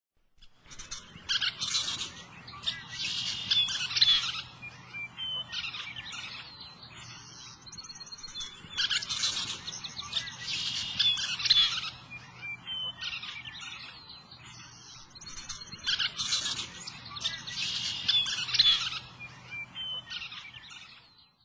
Western Kingbird
Tyrannus verticalis
Bird Sound
Song a series of "kip" notes followed by series of high-pitched fussy chittering notes. Common call a sharp "kip" note.
WesternKingbird.mp3